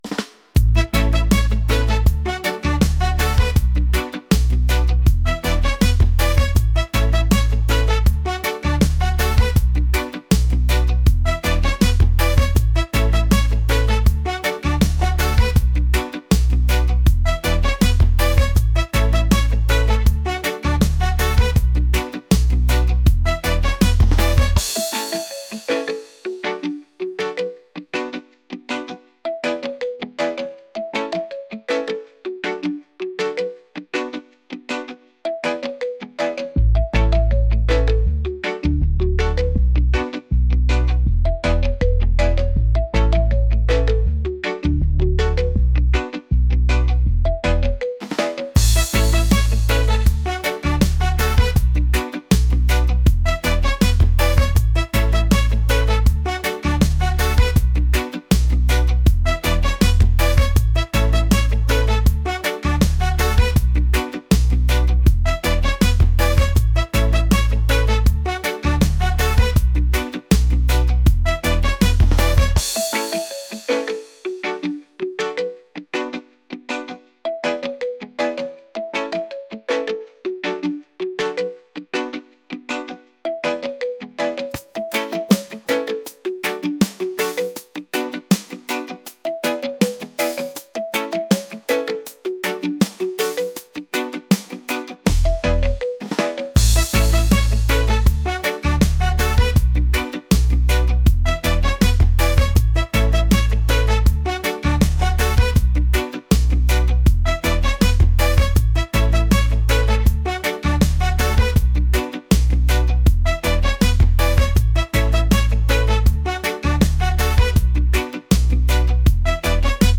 reggae | funk | pop